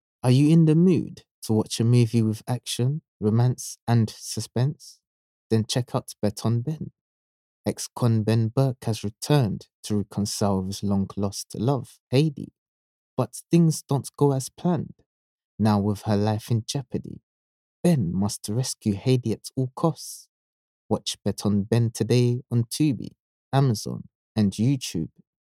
English (Caribbean)
Yng Adult (18-29) | Adult (30-50)